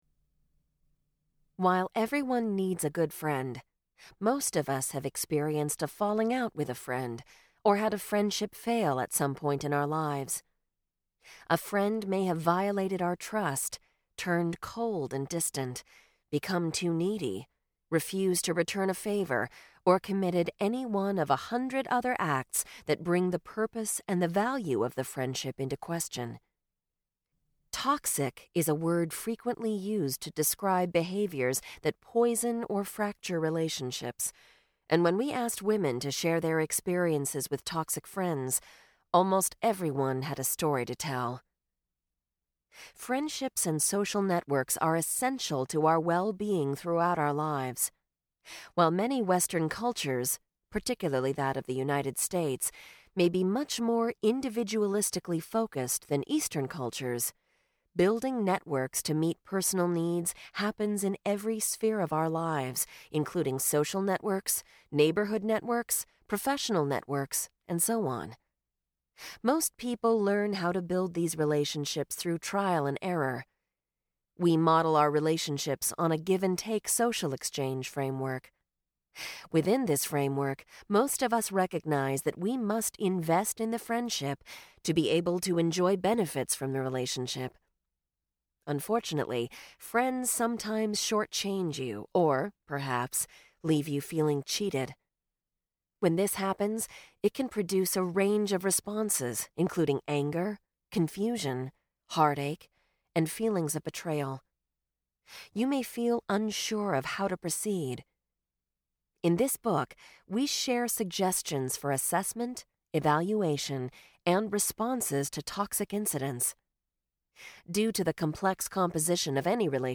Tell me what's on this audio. Genre: Audiobooks.